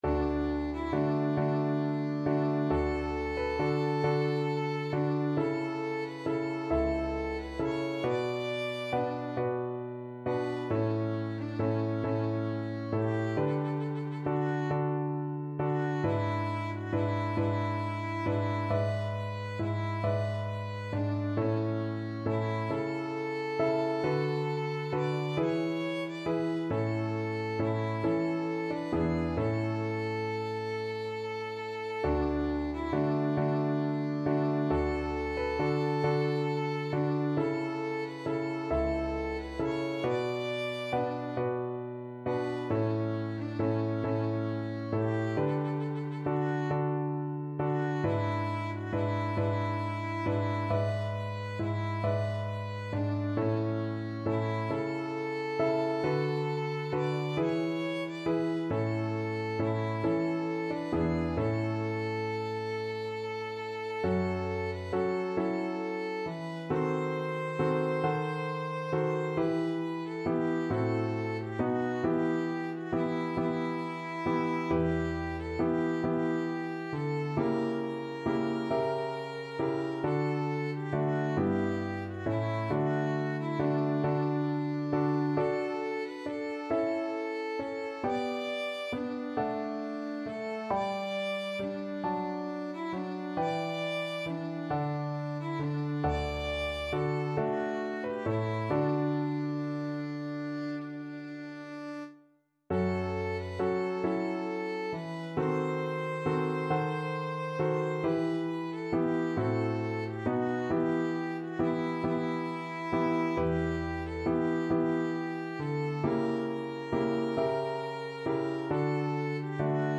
Classical Vivaldi, Antonio Concerto for Flute and String Orchestra 'Il Gardellino', Op.10, No.3, 2nd movement Violin version
D major (Sounding Pitch) (View more D major Music for Violin )
12/8 (View more 12/8 Music)
II: Larghetto cantabile .=45
Db5-D6
Violin  (View more Intermediate Violin Music)
Classical (View more Classical Violin Music)